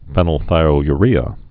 (fĕnəl-thīō-y-rēə, fēnəl-)